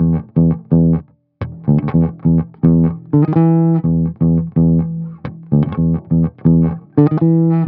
06 Bass Loop E.wav